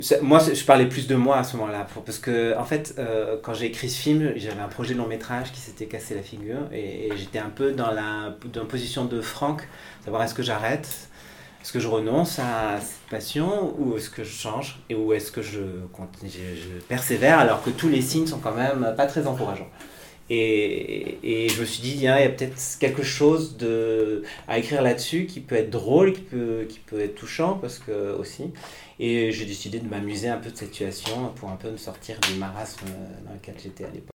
Lundi 17 juin 2019, Je promets d’être sage était en avant-première à l’UGC Saint Jean.